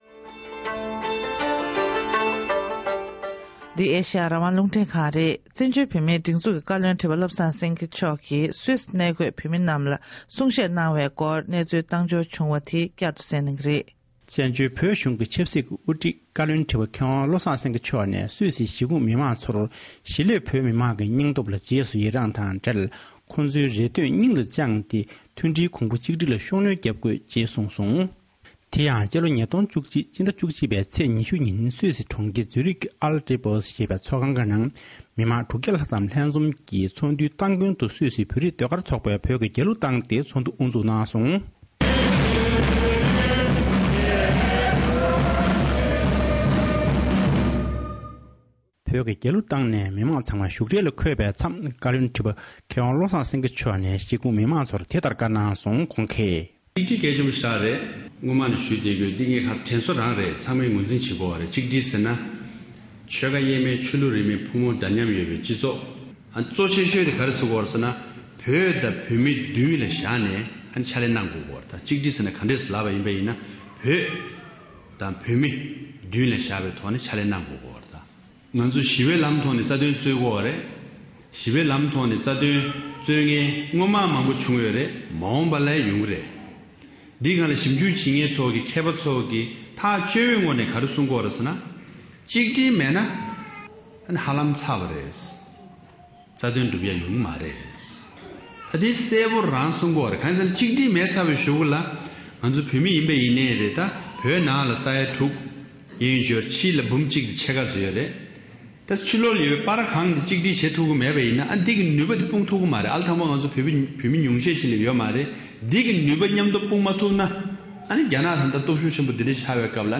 བཀའ་ཁྲི་མཆོག་ནས་སུད་སི་བོད་མིར་གསུང་བཤད།
སྒྲ་ལྡན་གསར་འགྱུར། སྒྲ་ཕབ་ལེན།